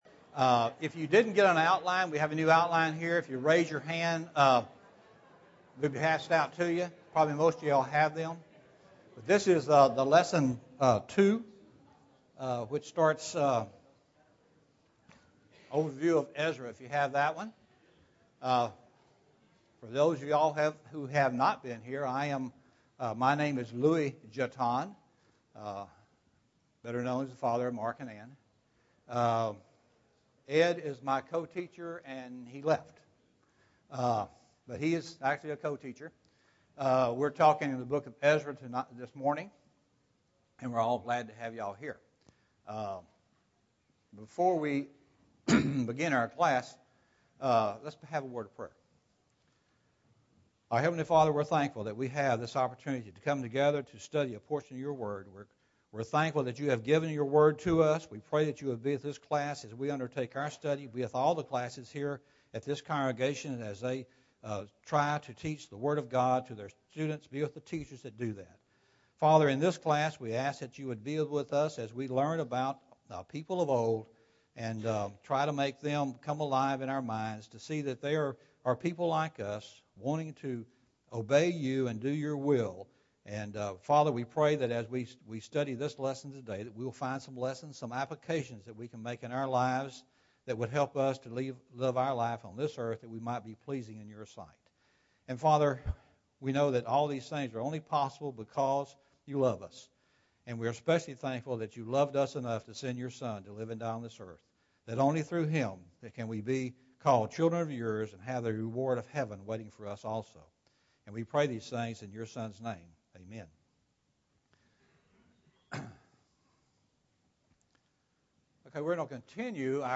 Ezra 1 (3 of 13) – Bible Lesson Recording